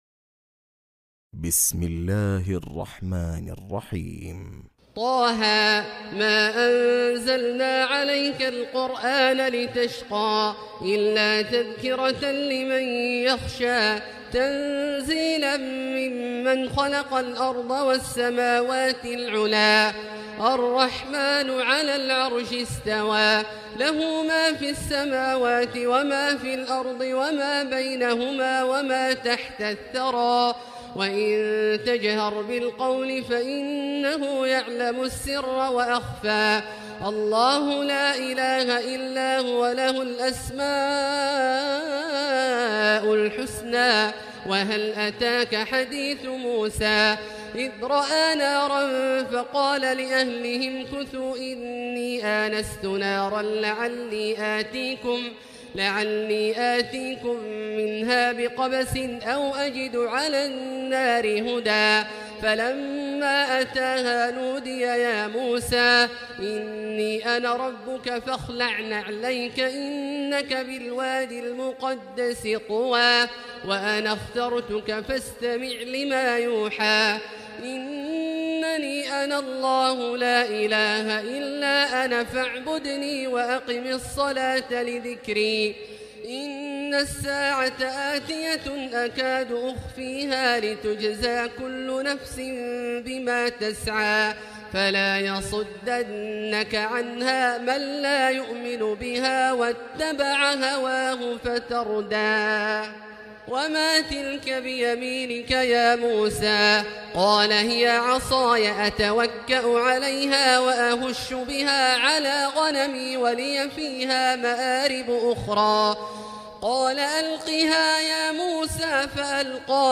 الحلقة الأولى | السور المكتملة من تلاوات التراويح لعام ١٤٣٩ هـ للشيخ عبدالله الجهني > سلسلة السور المكتملة من ليالي شهر رمضان المبارك | الشيخ د. عبدالله الجهني > المزيد - تلاوات عبدالله الجهني